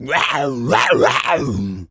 tas_devil_cartoon_02.wav